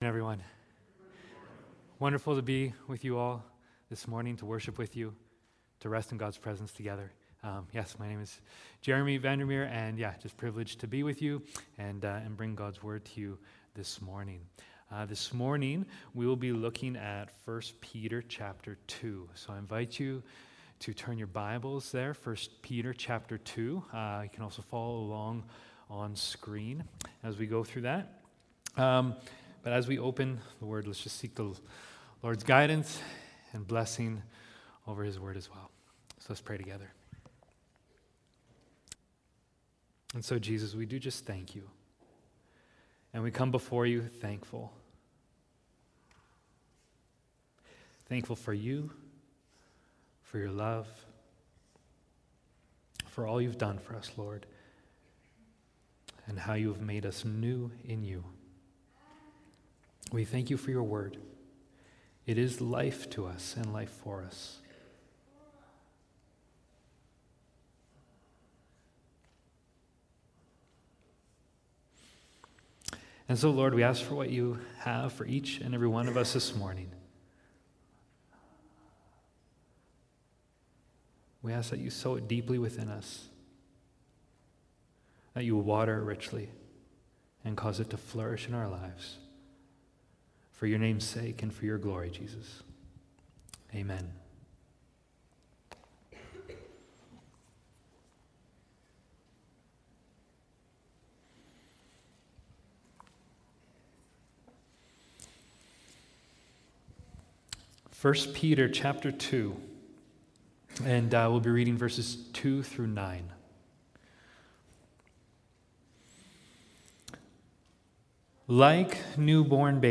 Sermons | Ebenezer Christian Reformed Church